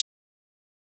hihat 7.wav